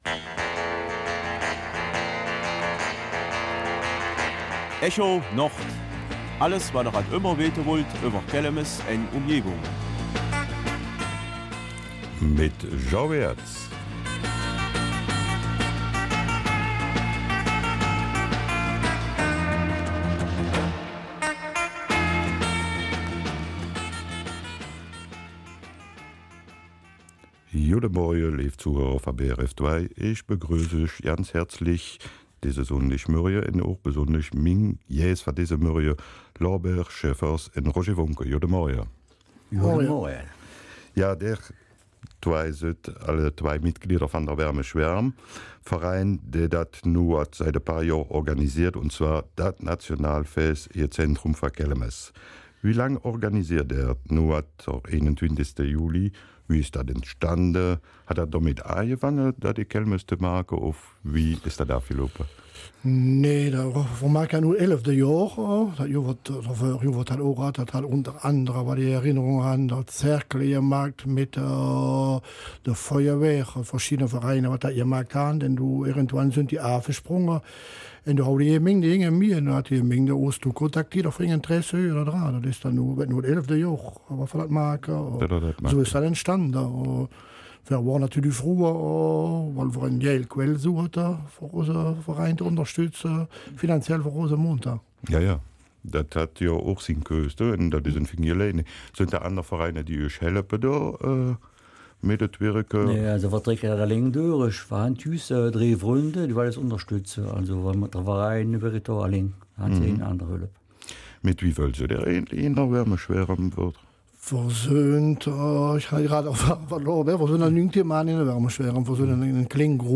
Kelmiser Mundart: Volksfest in Kelmis am 21. Juli